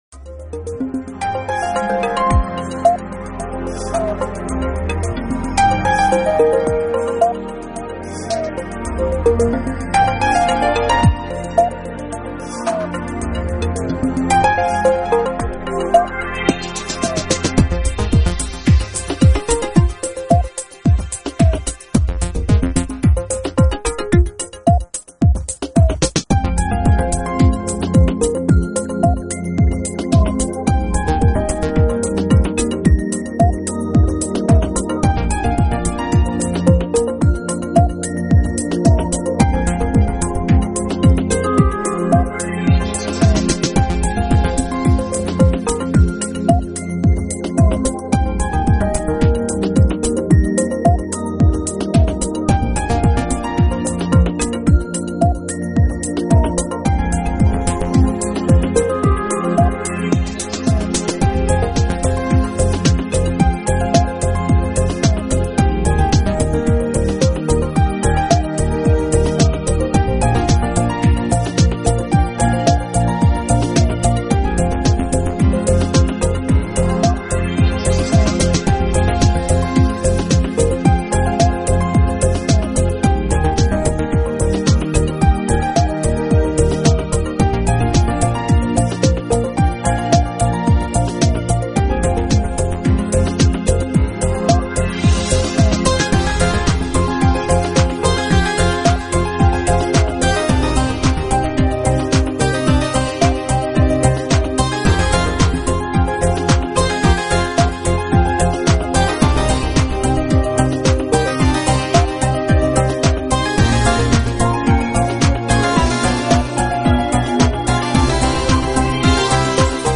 【新世纪音乐】